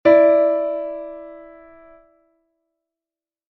Disonancia 7ª m (Mi - Re)